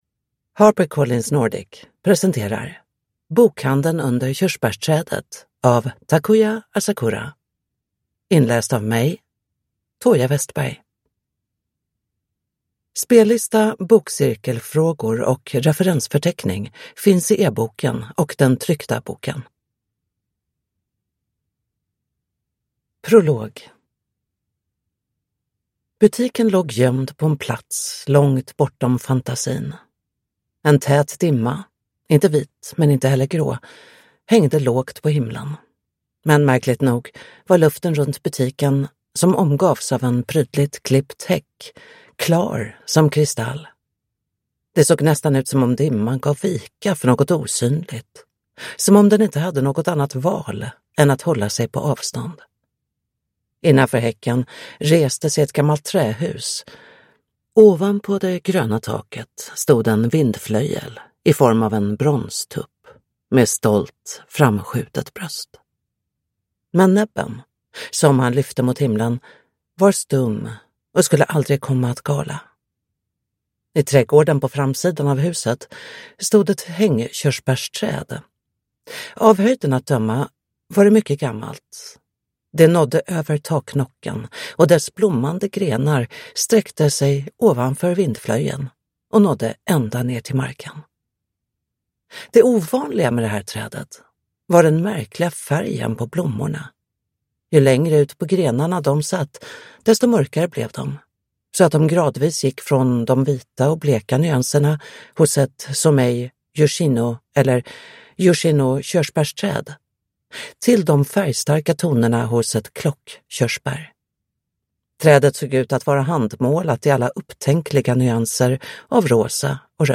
Bokhandeln under körsbärsträdet – Ljudbok